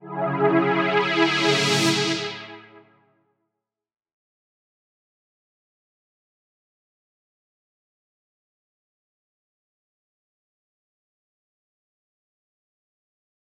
Pad Funk.wav